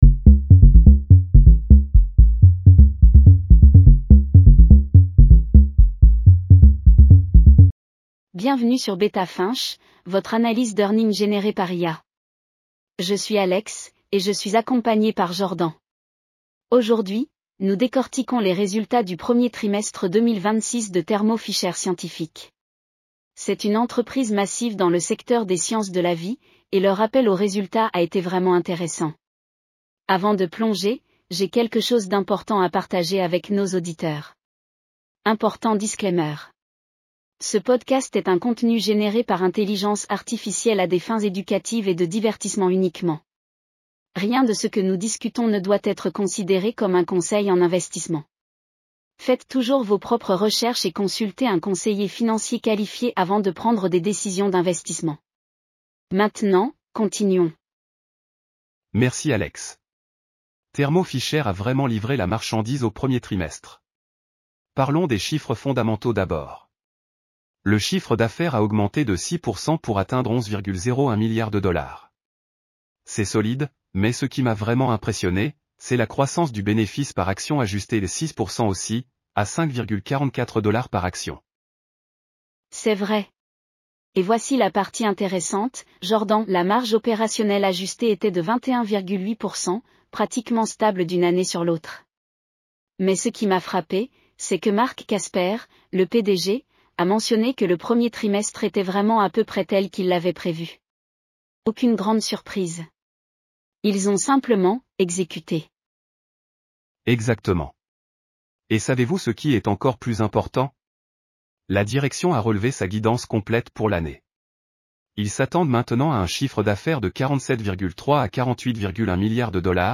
Thermo Fisher Scientific Q1 2026 earnings call breakdown. Full transcript & podcast. 9 min. 5 languages.